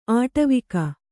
♪ āṭavika